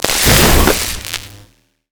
electric_surge_blast_04.wav